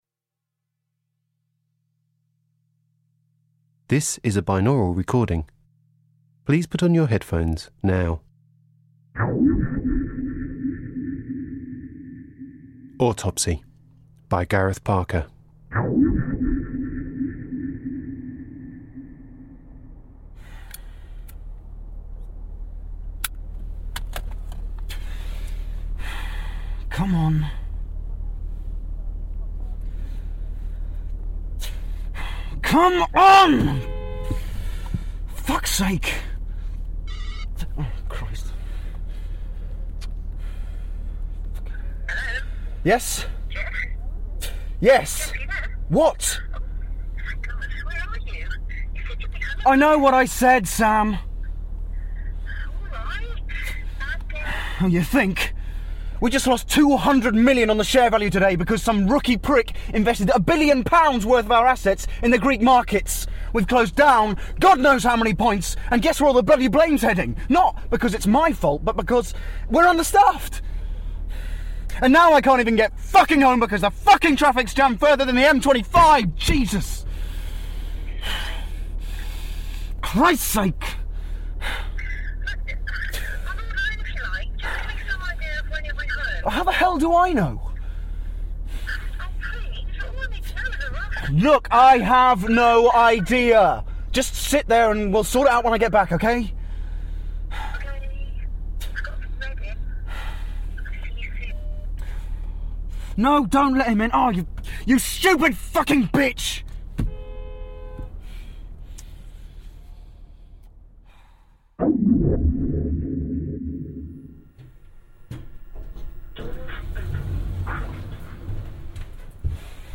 A man under pressure. A heated argument. A fatal heart attack. This should have been the day Jeff died. Except this time, his death was only the beginning… This is a binaural recording – best listened to through headphones!